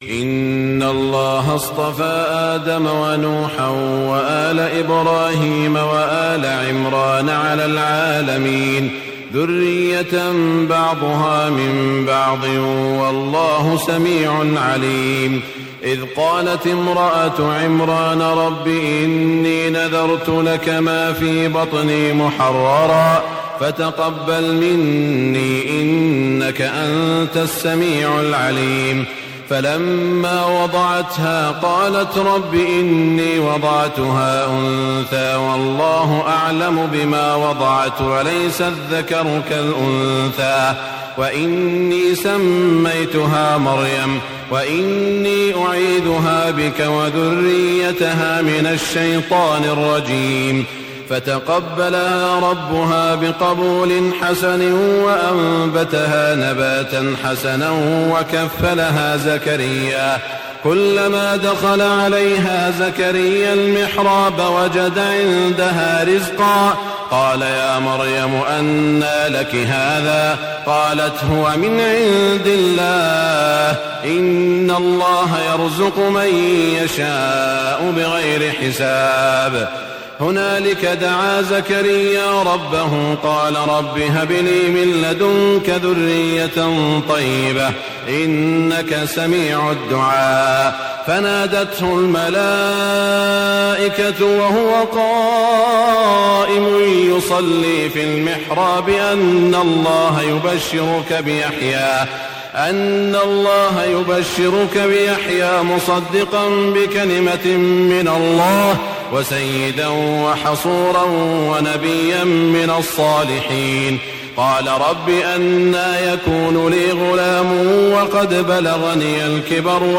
تهجد ليلة 23 رمضان 1423هـ من سورة آل عمران (33-92) Tahajjud 23 st night Ramadan 1423H from Surah Aal-i-Imraan > تراويح الحرم المكي عام 1423 🕋 > التراويح - تلاوات الحرمين